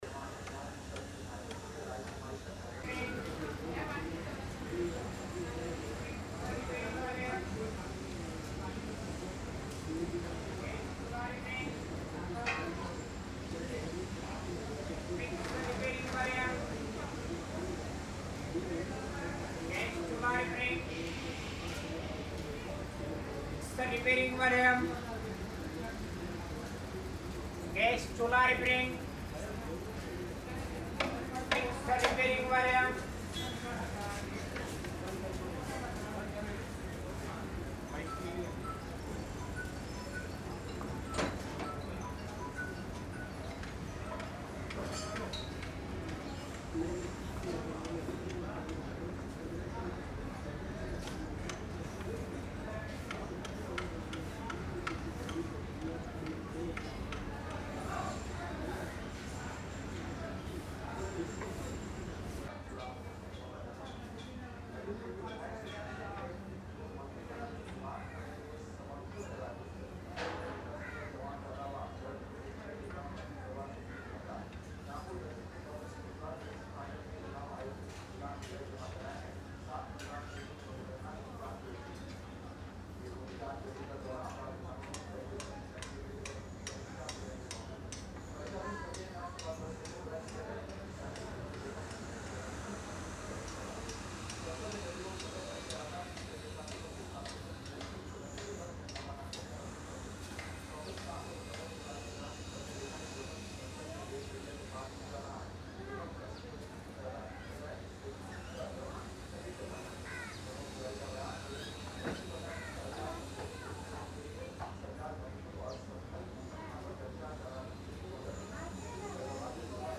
Hawker Voice – AMB-021
This audio captures an authentic hawker’s call in a classic Mumbai chawl environment, filled with natural reverberation, casual background chatter, utensils, and distant street noise. The sound reflects the true essence of community living—tight lanes, bustling activity, and the rhythmic tone of local vendors selling everyday essentials.
The texture of the recording adds depth, warmth, and a lived-in city feel to any project.
Twentyone-Chawl-Hustle-Street-Hawker.mp3